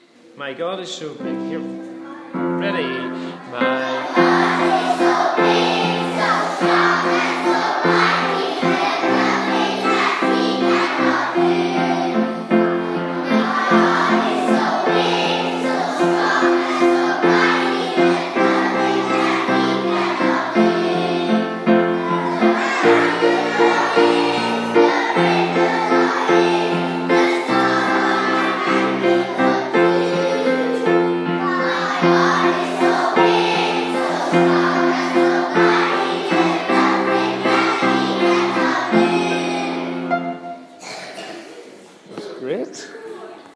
Have a listen to this group of 5 to 7 year olds singing ‘My God is So Big’